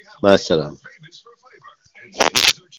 Pops Fart Efecto de Sonido Descargar
Pops Fart Botón de Sonido